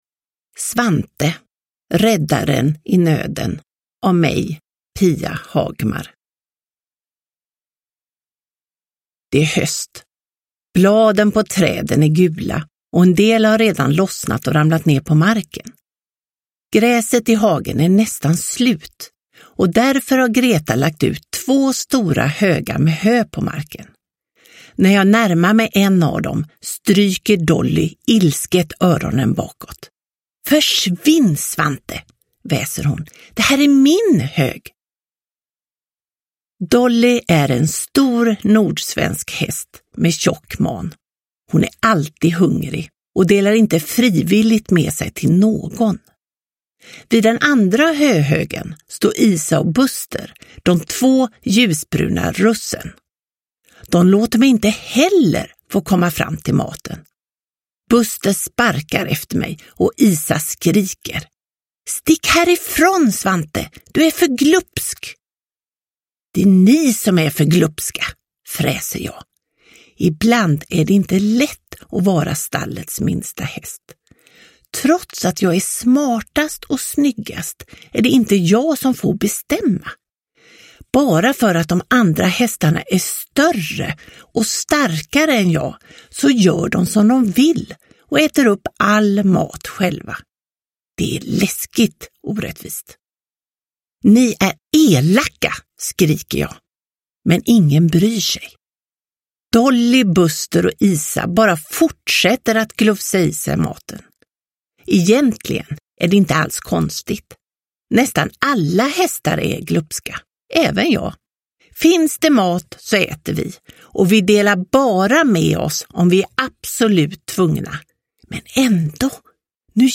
Räddaren i nöden – Ljudbok – Laddas ner